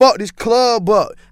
Tm8_Chant16.wav